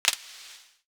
Sizzle Click 2.wav